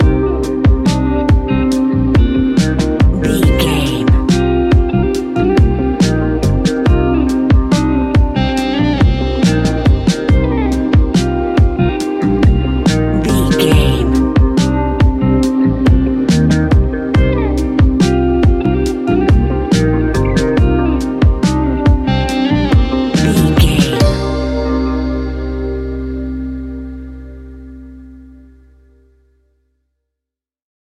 Ionian/Major
C♭
chill out
laid back
sparse
chilled electronica
ambient
atmospheric
instrumentals